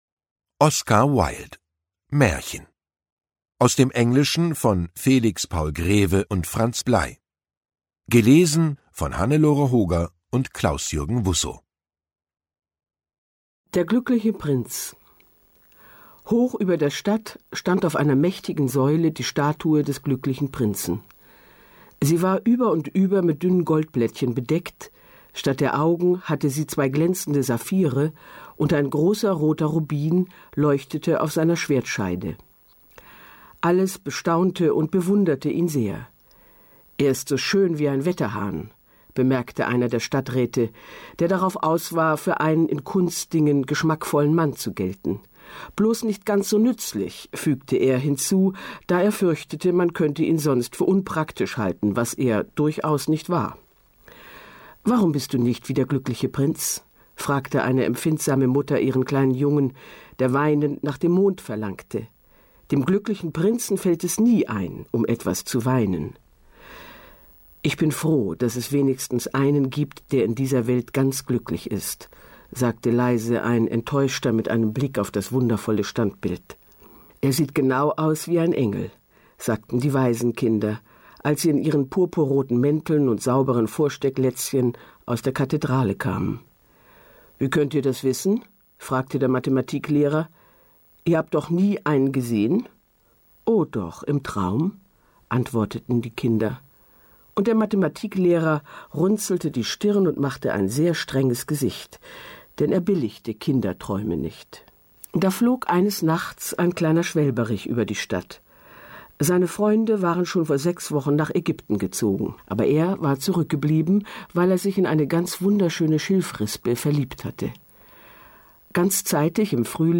Lesungen mit Hannelore Hoger und Klausjürgen Wussow (1 mp3-CD)
Hannelore Hoger, Klausjürgen Wussow (Sprecher)
»Die Hörbuch-Edition ›Große Werke. Große Stimmen.‹ umfasst herausragende Lesungen deutschsprachiger Sprecherinnen und Sprecher, die in den Archiven der Rundfunkanstalten schlummern.« SAARLÄNDISCHER RUNDFUNK